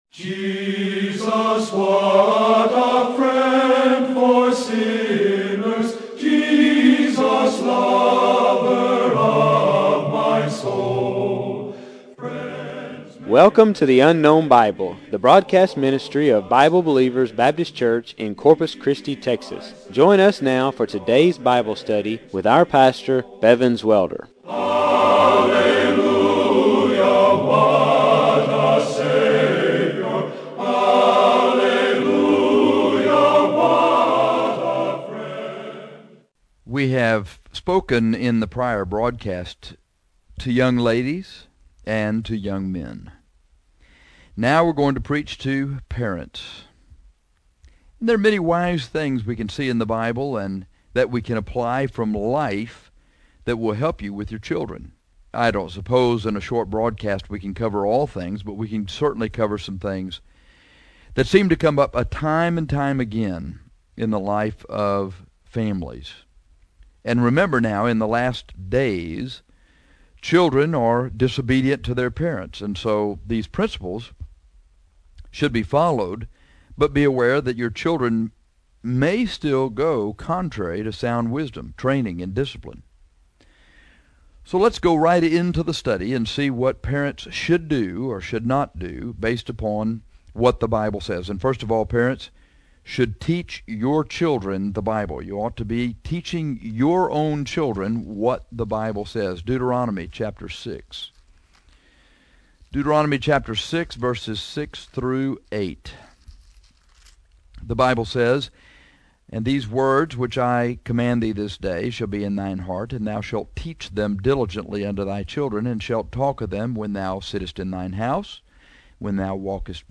Now we are going to preach to parents. There are many wise things we can see in the Bible and that we can apply from life that will help you with your children.